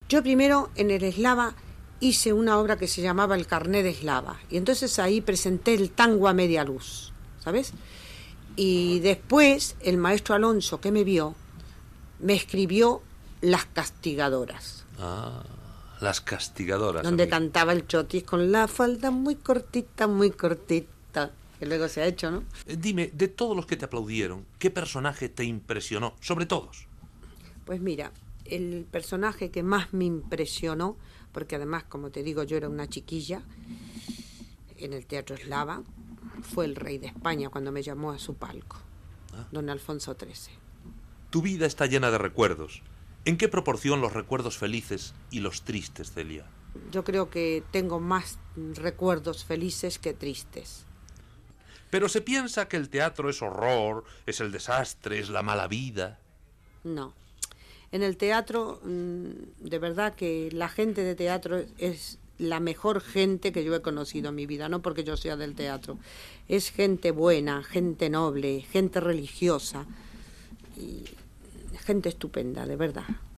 Entrevista a l'actriu i cantant Celia Gámez que recorda alguna de les seves cançons i les seves actuacions al Teatre Eslava de Madrid
Entreteniment